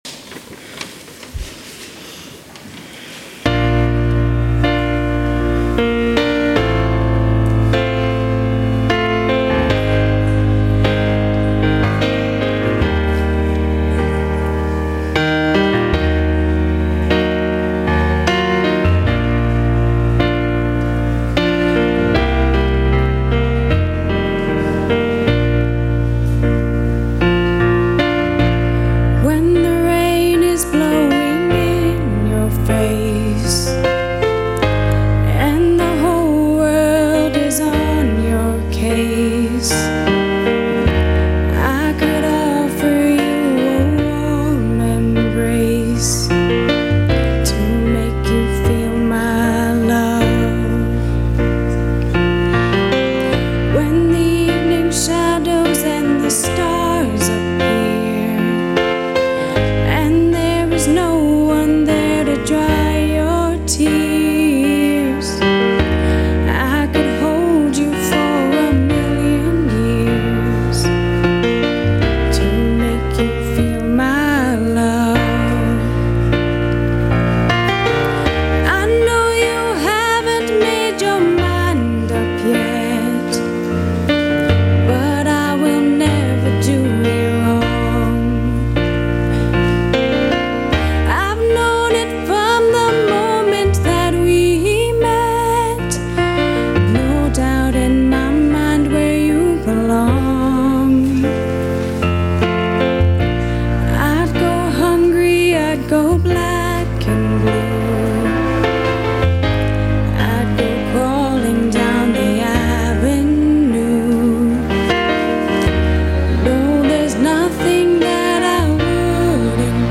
Musical Performance Feel My Love - The People of St. John's